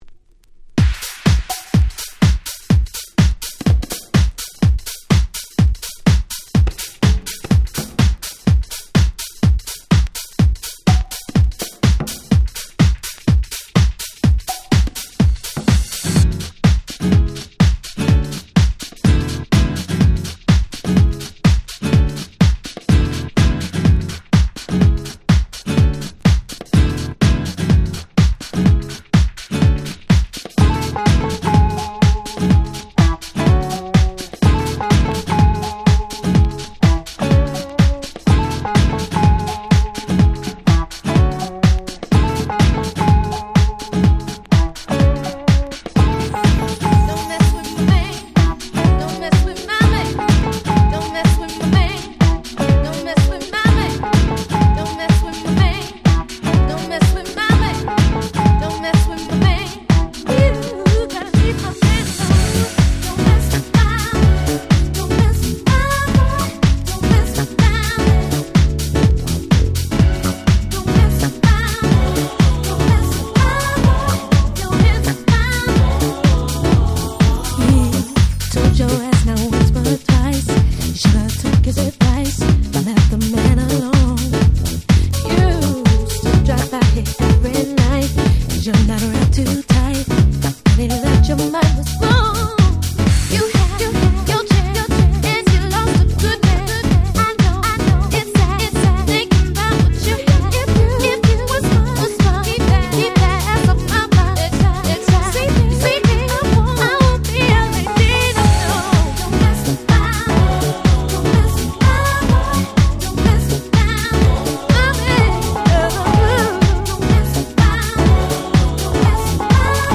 00’ Smash Hit R&B !!
R&B好きでも全く違和感なく聴くことが出来るであろう最高の歌物Houseに進化！！